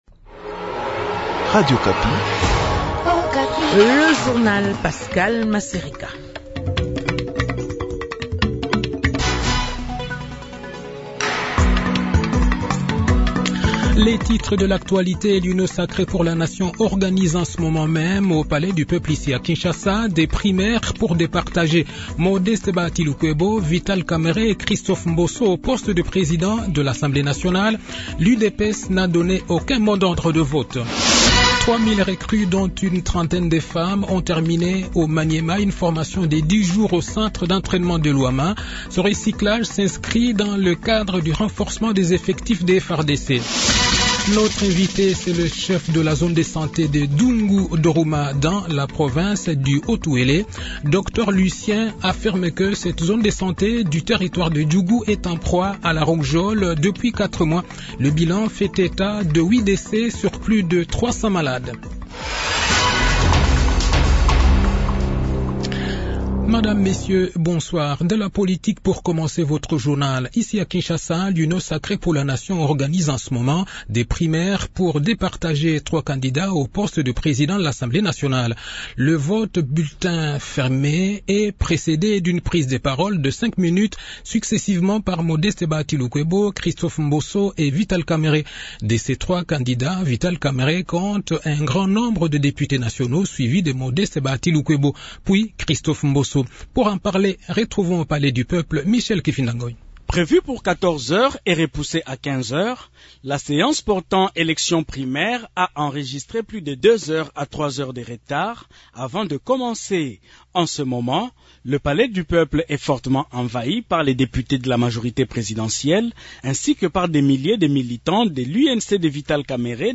Le journal Soir